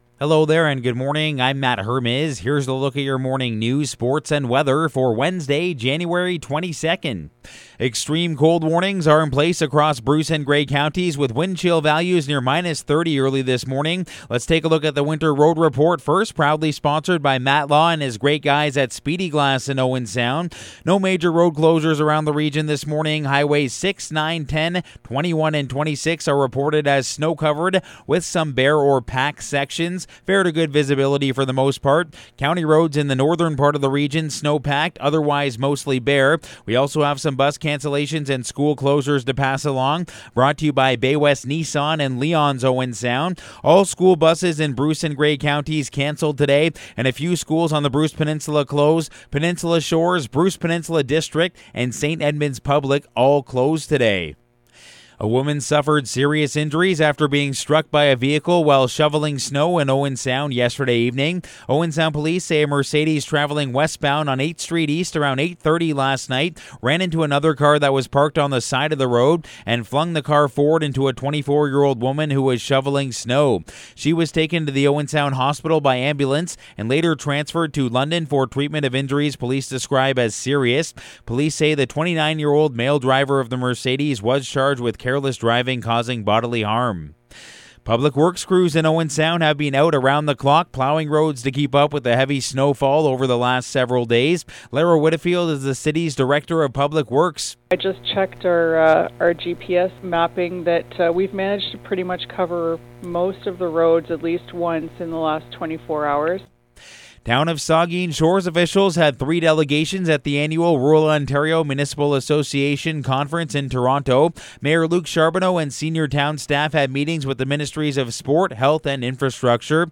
Morning News – Wednesday, January 22